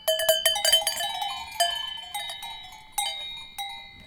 Dans cette page nous offrons des sonneries issues d’enregistrements de troupeaux.
Belledonne (Alpes)
Sonnettes Varrone Premana et Devouassoud
belledonne_texto-624.ogg